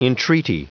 Prononciation du mot entreaty en anglais (fichier audio)
Prononciation du mot : entreaty